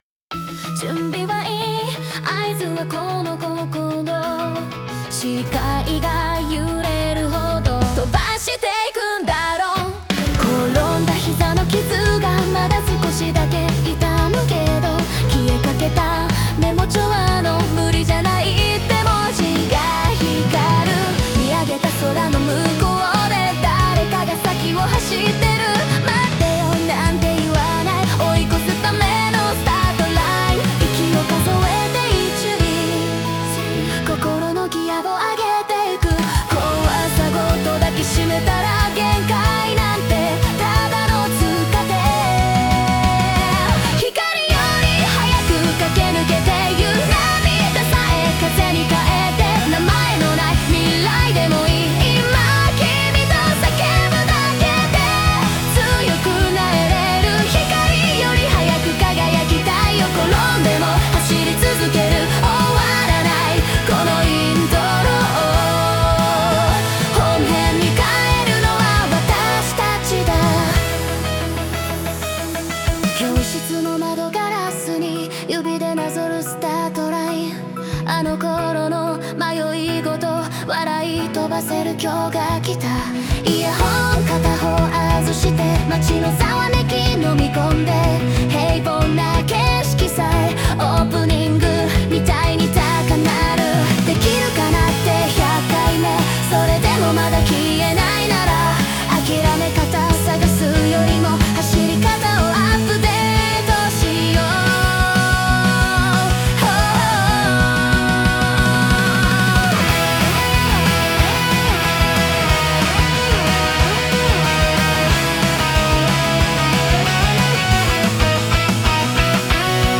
3:23 王道アニソン・オープニング「光より速く」